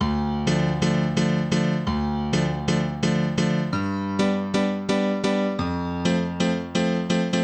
BAL Piano Riff D-G-F.wav